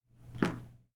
footstep_tile_2.ogg